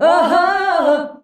AHAAH G.wav